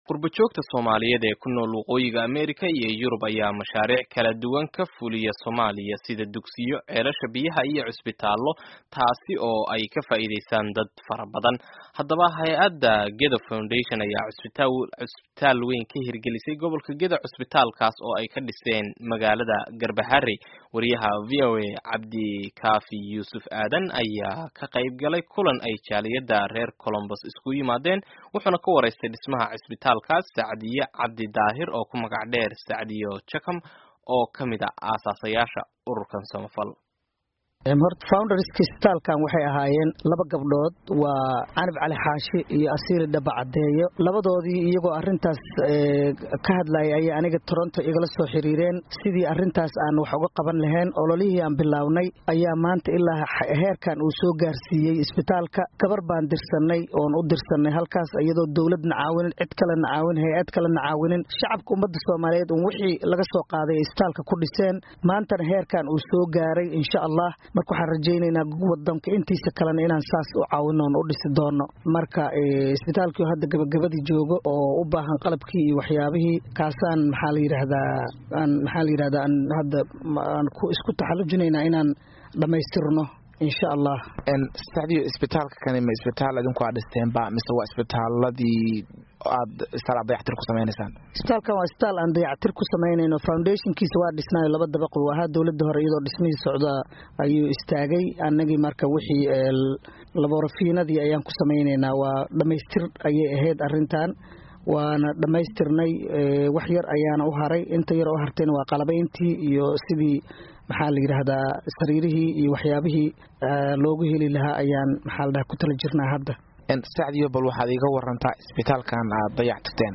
Waraysiga Gedo Foundation